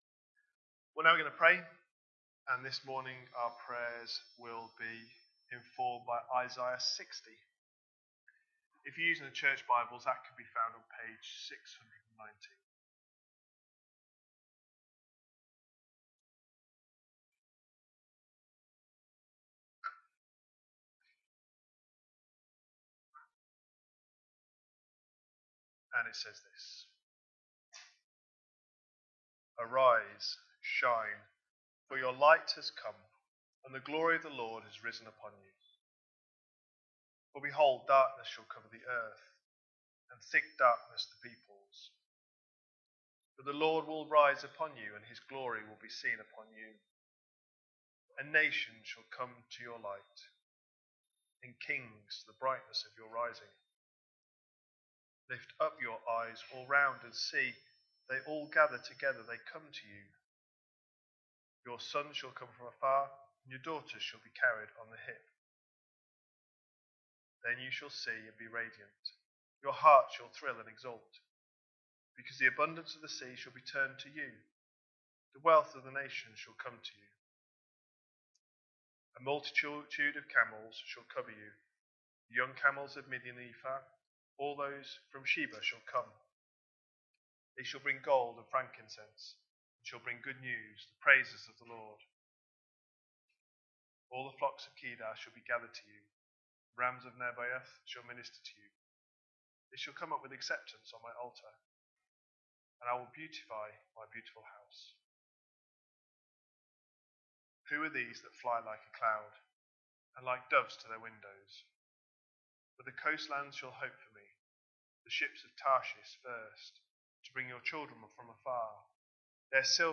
The latest sermons from Trinity Church Bradford.